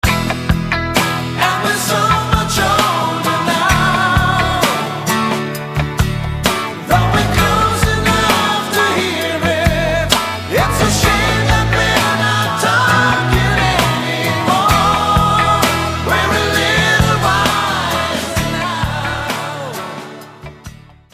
keyboards, bass, guitars
lead and background vocals
drums
acoustic guitar
guitar solos
trumpet
saxophones